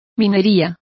Complete with pronunciation of the translation of mining.